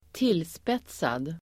Ladda ner uttalet
tillspetsad adjektiv (om yttrande), incisive [used of remarks]Uttal: [²t'il:spet:sad] Böjningar: tillspetsat, tillspetsadeDefinition: provokativ och förenklad